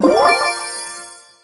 pickup_spawn_03.ogg